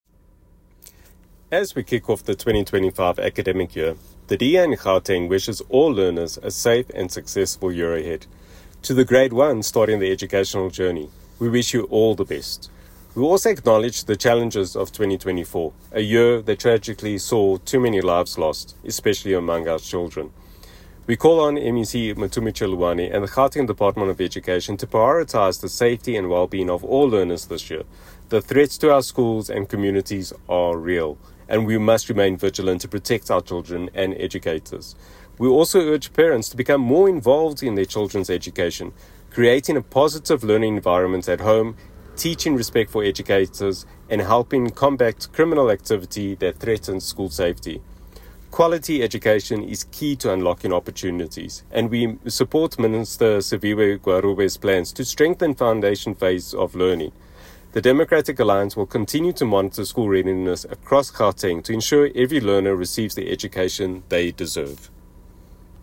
English soundbite by Sergio Isa Dos Santos MPL.